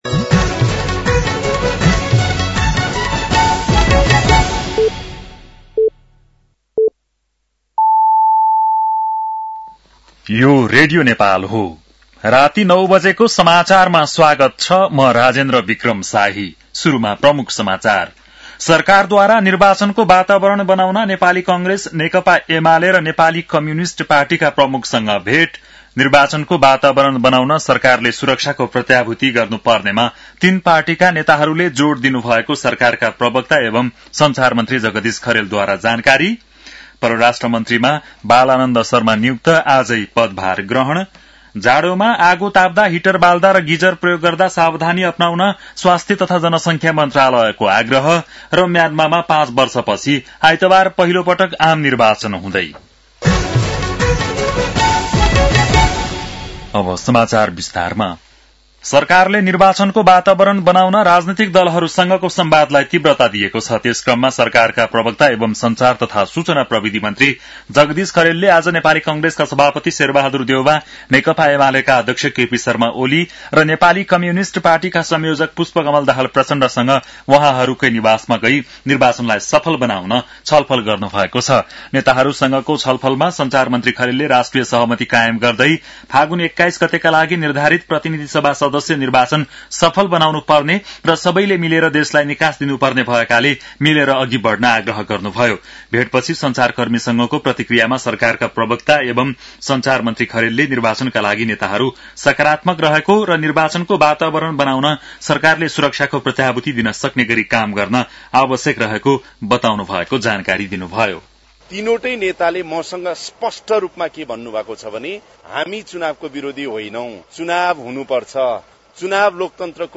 बेलुकी ९ बजेको नेपाली समाचार : ११ पुष , २०८२
9-pm-nepali-news-9-11.mp3